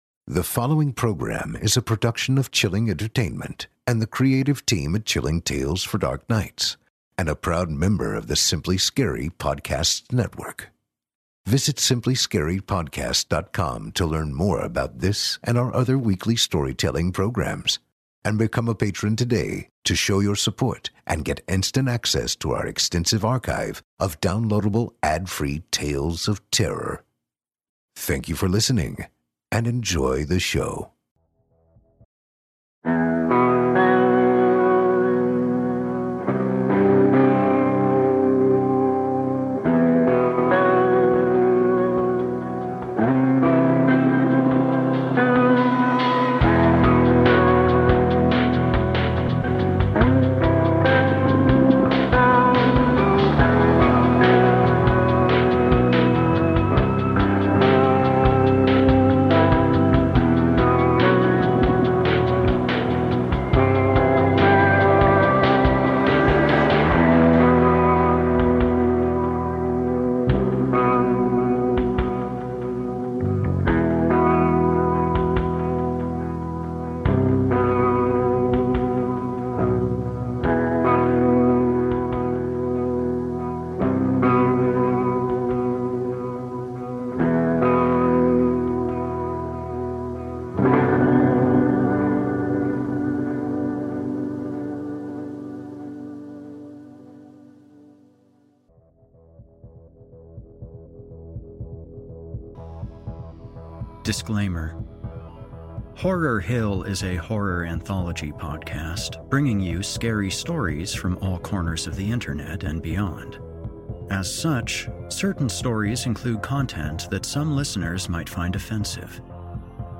Horror Hill: A Horror Anthology and Scary Stories Series Podcast / S8E20 - "Deader Days" - Horror Hill